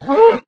cowhurt2.ogg